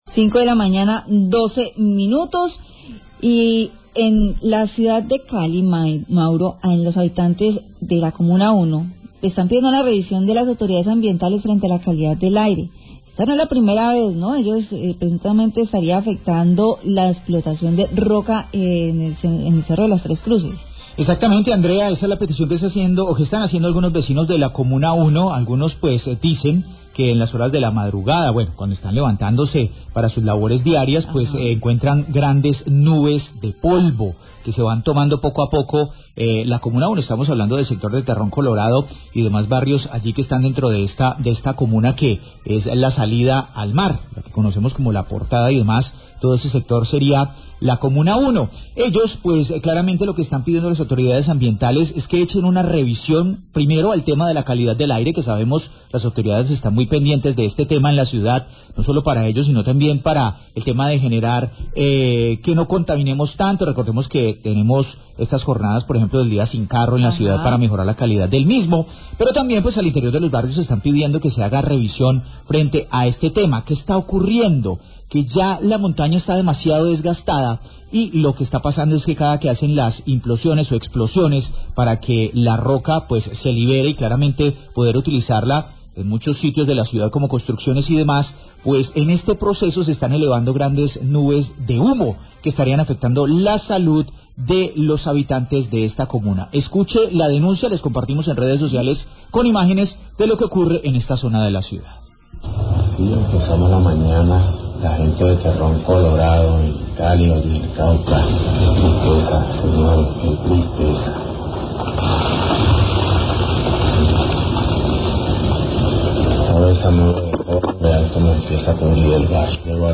Radio
El periodista hace un llamado a la CVC y el DAGMA para que revisen esta situación.